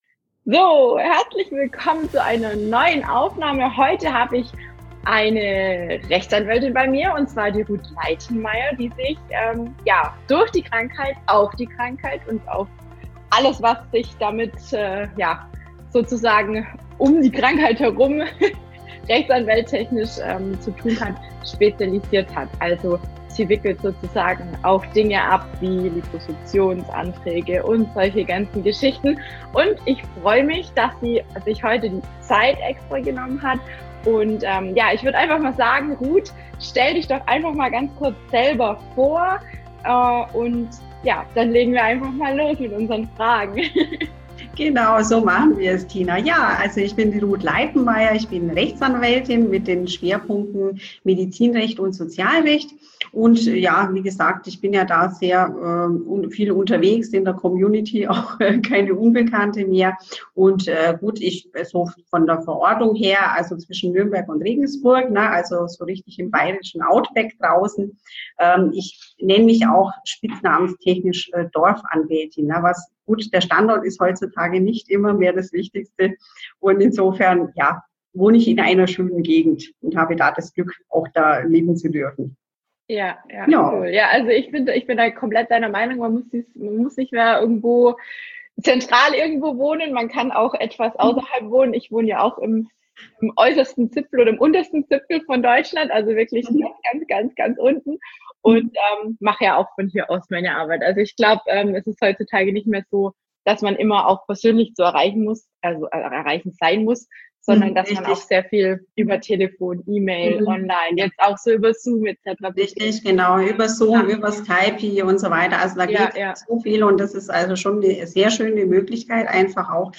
Nicht wundern, wir hatten zwischendurch immer mal wieder eine streikende Internetverbindung, trotzdem ist es eine tolle Aufnahme geworden, der auch noch weitere Folgen werden.